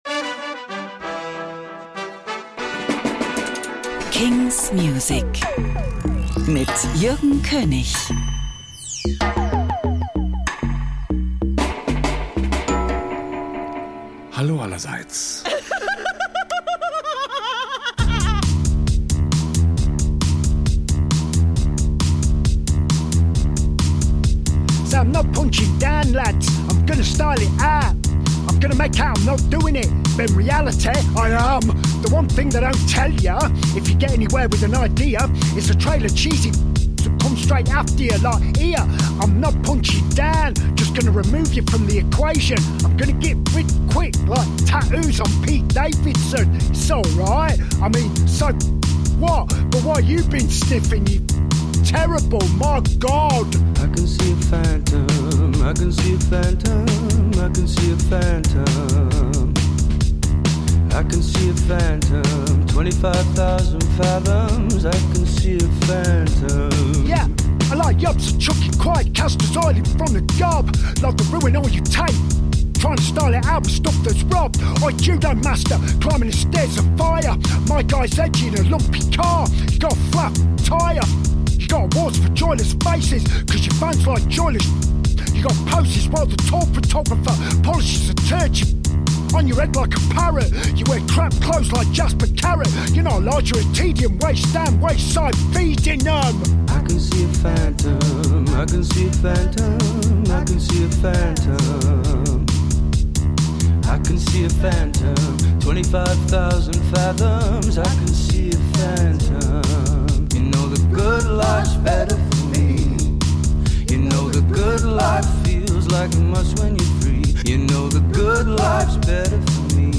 new indie & alternative releases.